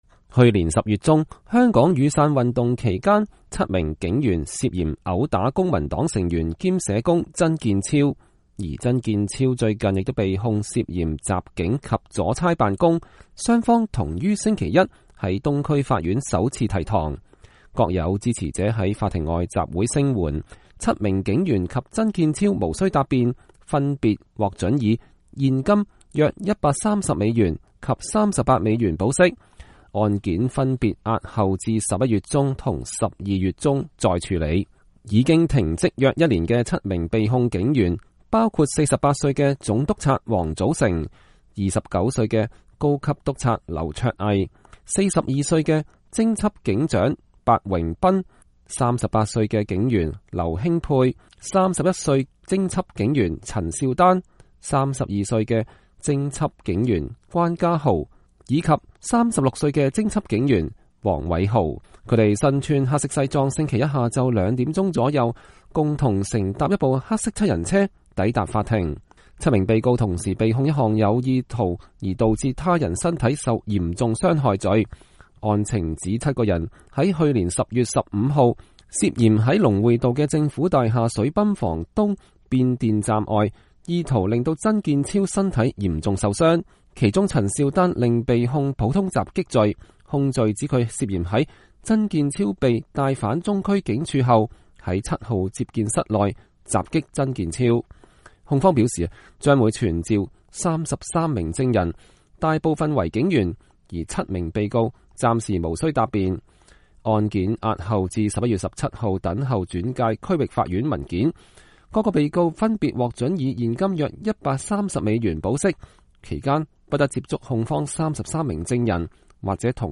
支持警員集會人士高呼口號：不怕屈壓、香港良心、最愛警察。